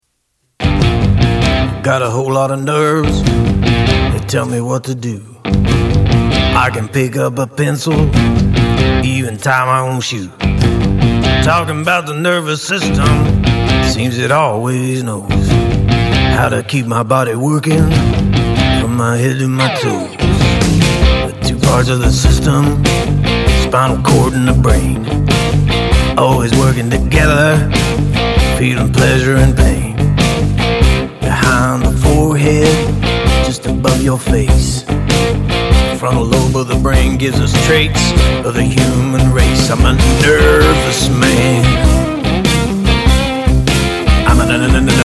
Learn about the nervous system with this bluesy song!